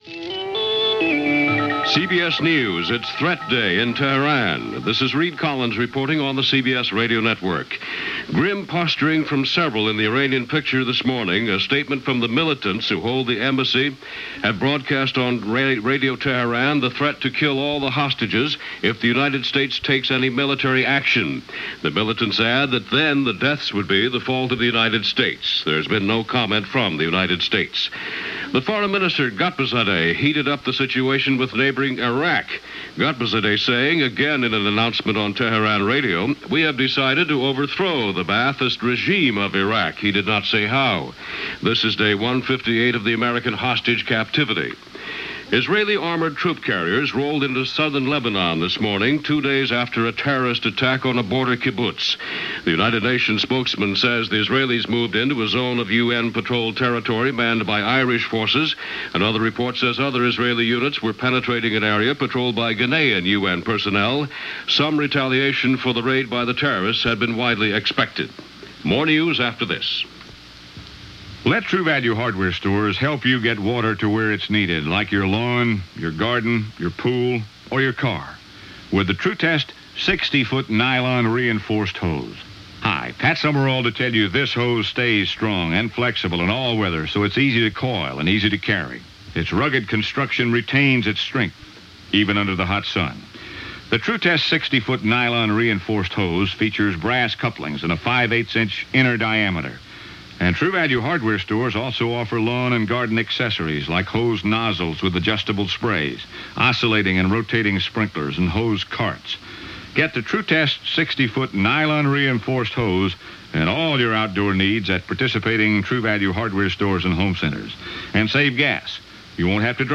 CBS News – Newsbreak – The World Tonight